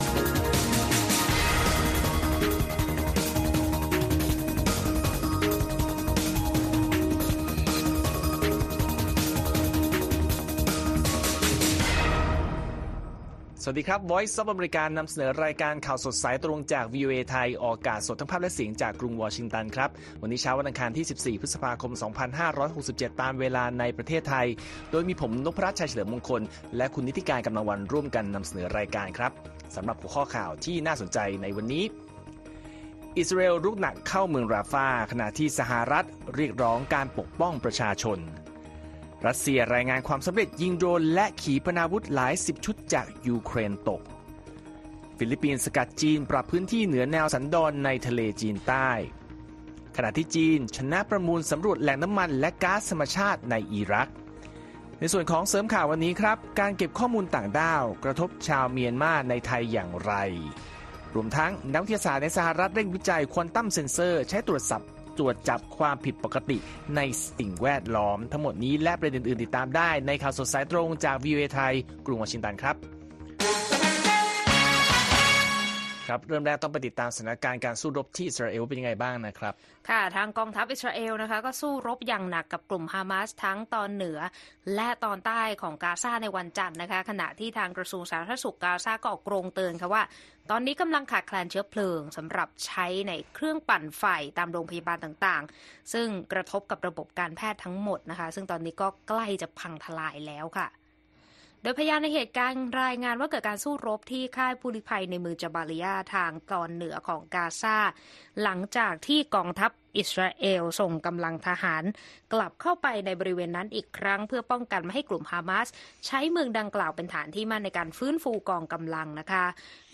ข่าวสดสายตรงจากวีโอเอไทย 6:30 – 7:00 น. วันอังคารที่ 14 พฤษภาคม 2567